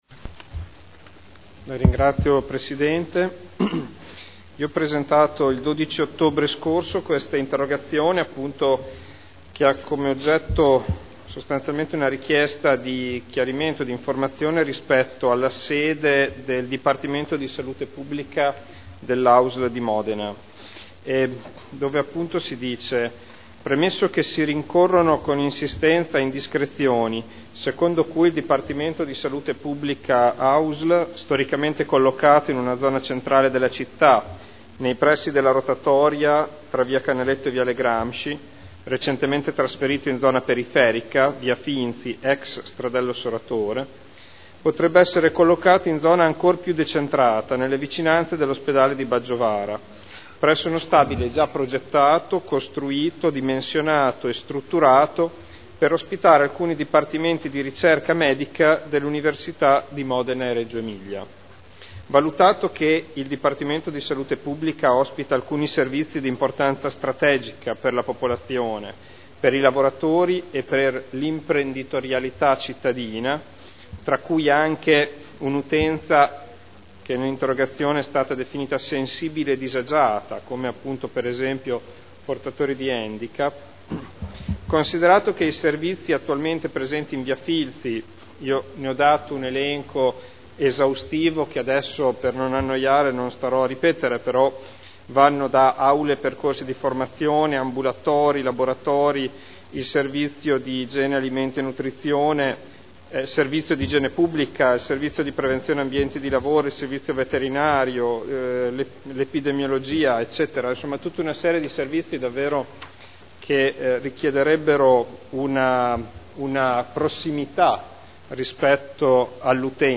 Seduta del 24/02/2011. Interrogazione del consigliere Ricci (Sinistra per Modena) avente per oggetto: “Sede DSP Ausl” (presentata il 12 ottobre 2010 – in trattazione il 24.2.2011)